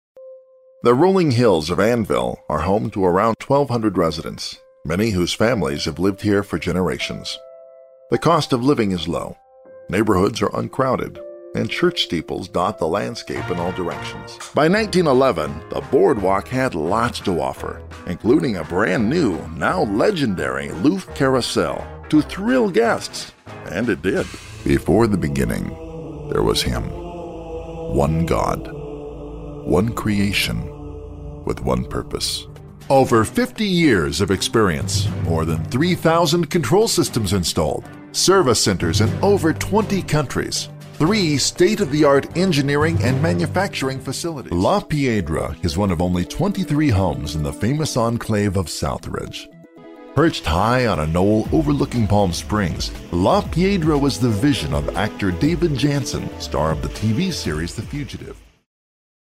Resonant, deep baritone voice with a warm delivery.
Narration
Mid-western, west coast American English
Narration Compilation.mp3